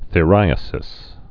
(thĭ-rīə-sĭs, thī-)